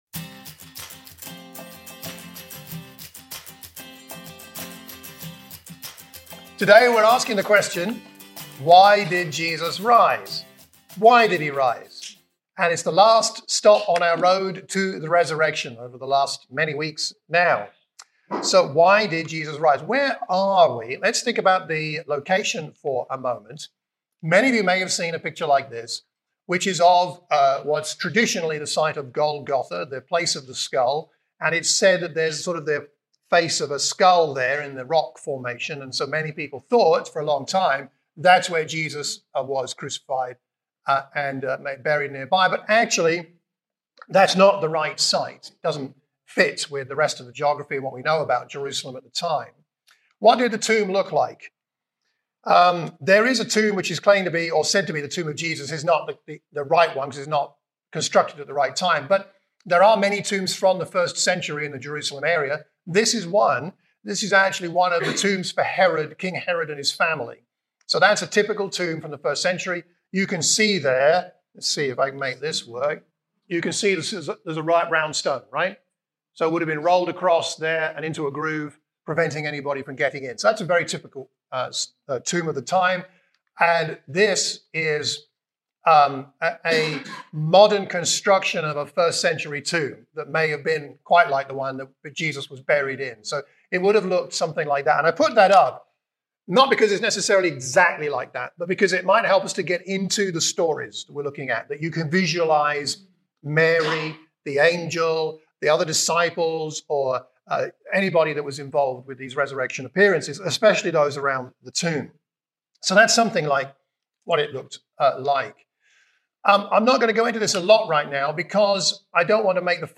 A sermon for the Watford Church of Christ.